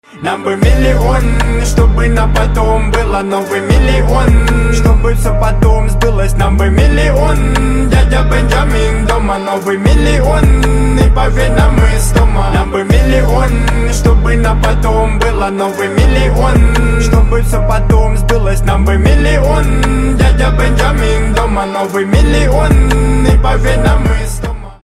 • Качество: 320, Stereo
пацанские
качающие
мужские